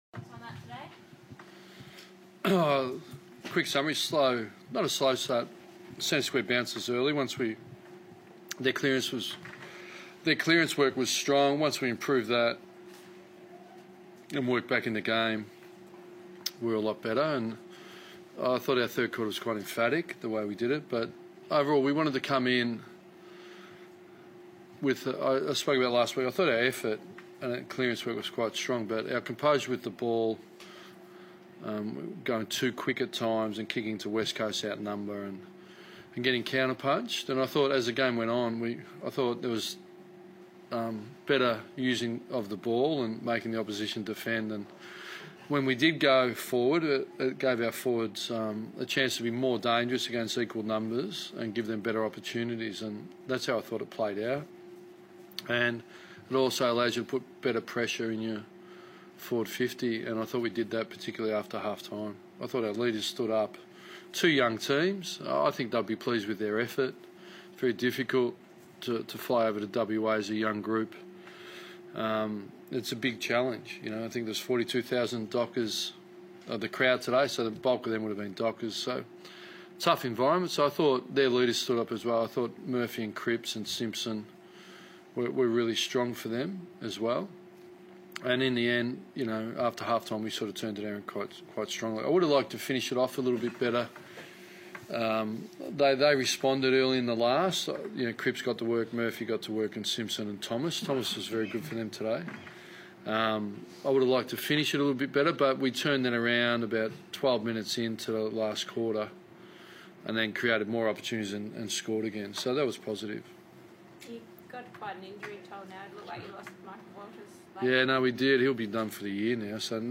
Ross Lyon spoke to the media following the win over Carlton.